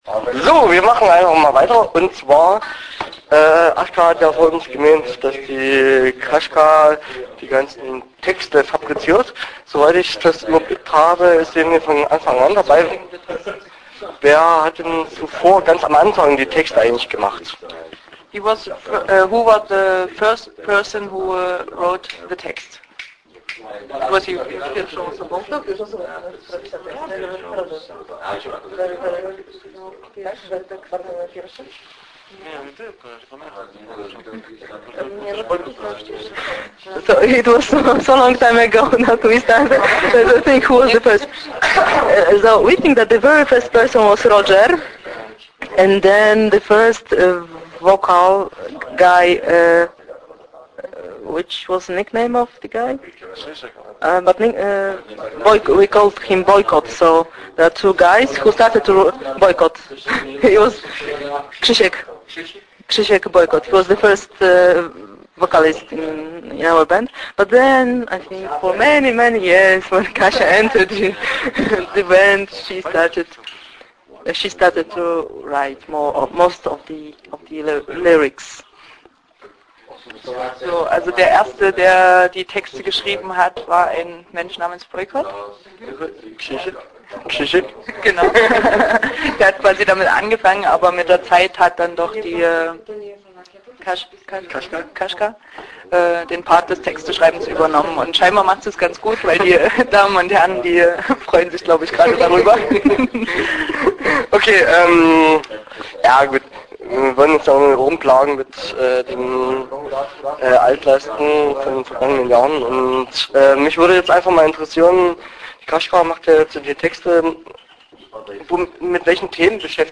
Interview Teil 1 (14:47)